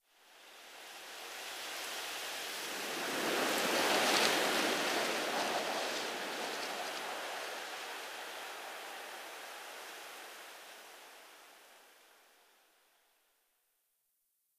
windwhistle5.ogg